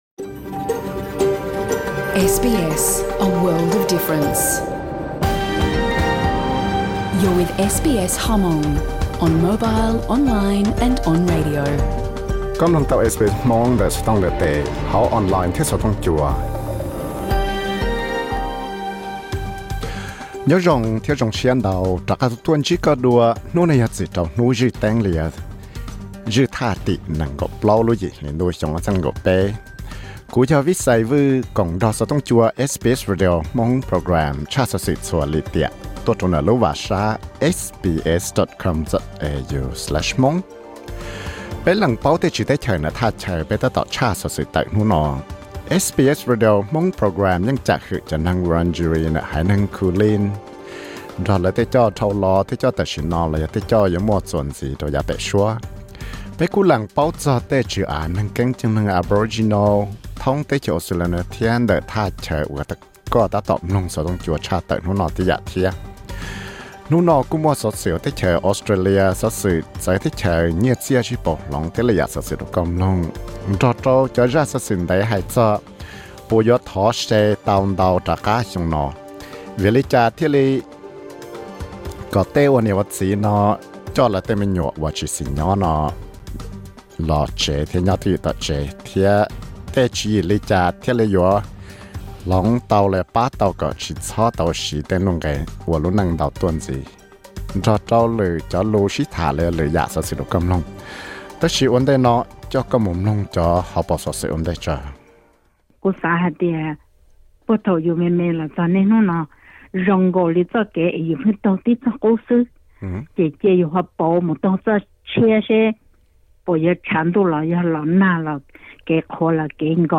Thursday news program